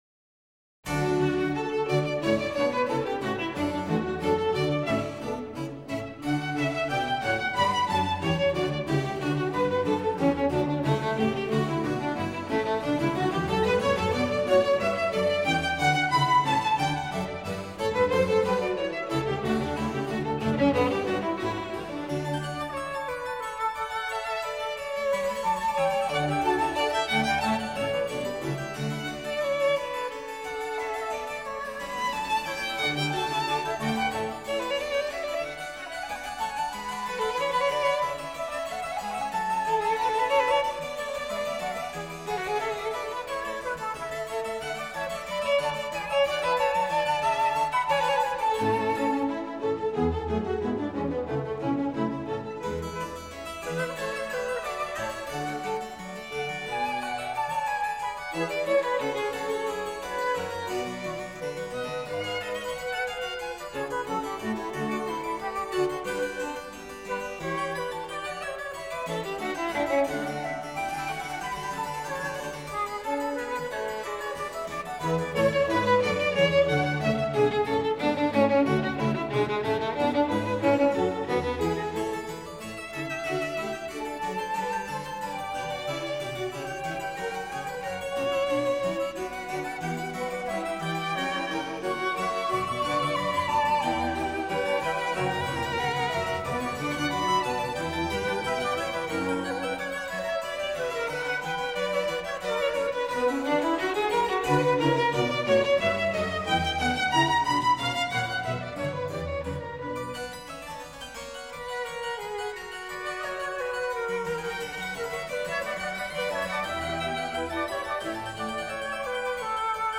Concerto grosso
Musical material is passed between a small group of soloists (the concertino) and full orchestra (the ripieno or tutti).